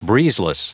Prononciation du mot breezeless en anglais (fichier audio)
Prononciation du mot : breezeless